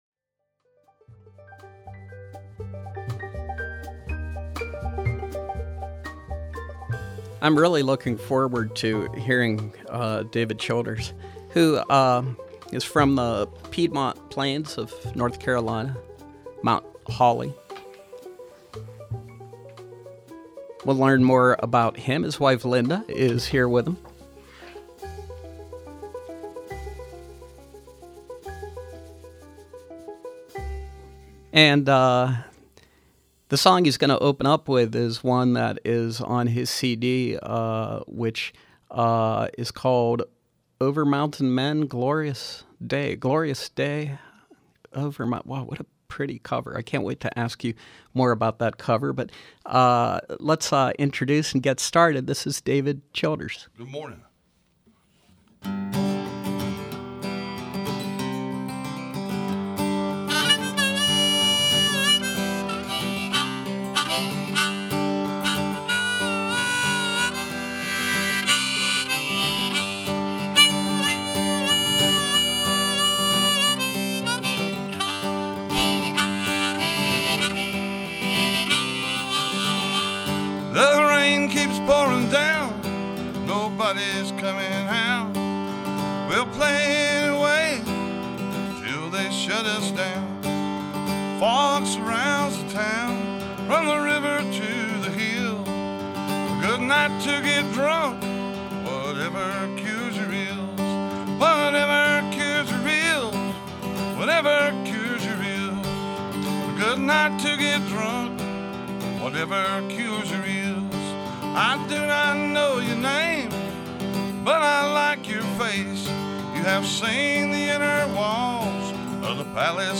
Live music with singer/songwriter